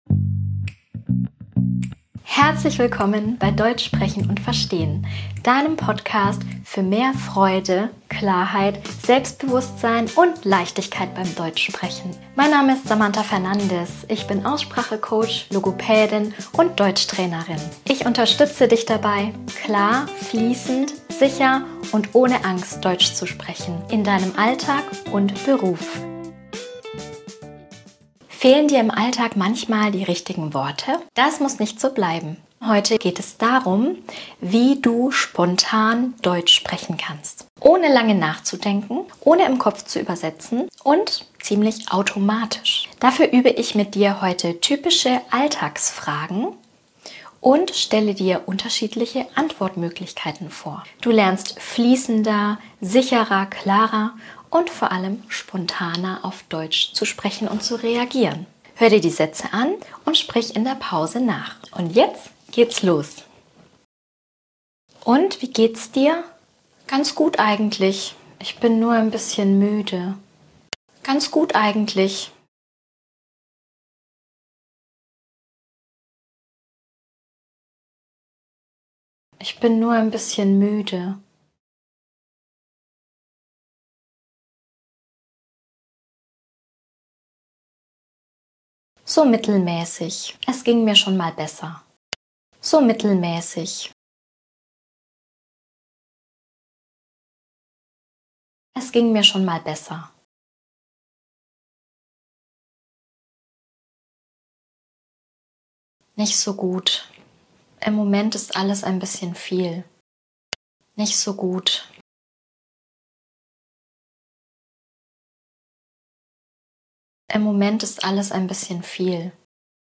In dieser Podcastfolge übst du, spontan Deutsch auf C1-Niveau zu sprechen, ohne lange nachzudenken und ohne im Kopf zu übersetzen. Du hörst typische Alltagsfragen und mehrere natürliche Antwortmöglichkeiten, die du direkt in deinem Alltag verwenden kannst. Hör zu sprich in den Pausen laut nach und entwickle ein sicheres Sprachgefühl für echtes, spontanes Deutsch.